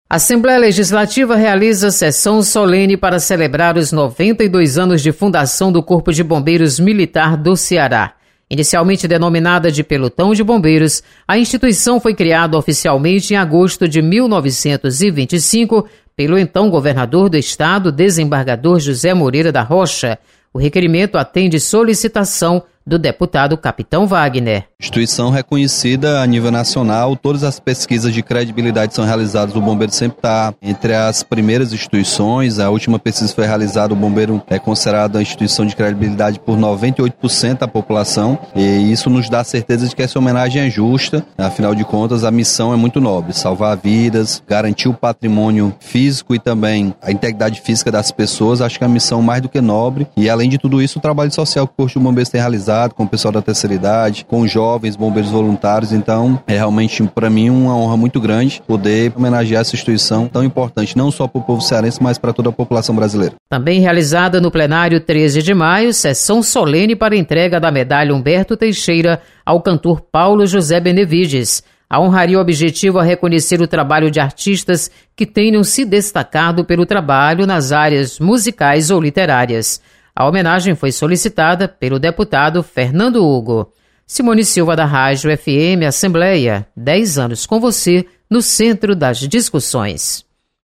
Sessão Solene
Repórter